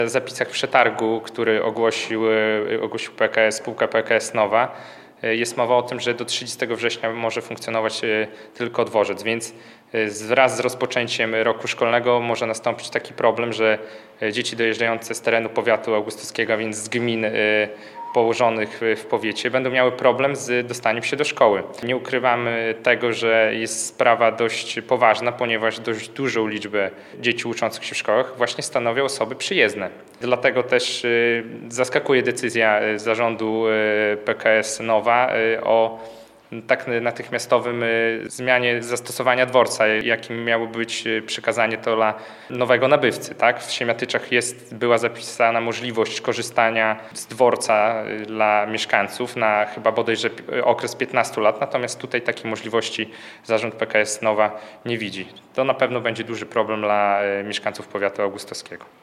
Micha Kotarski, radny powiatu augustowskiego zwraca też uwagę na potencjalny problem z dowozem uczniów z okolicznych gmin. Zdaniem samorządowca zmiana lokalizacji dworca może utrudnić dojazd do szkół.
Micha-Kotarski-radny-powiatu-augustowskiego-3.mp3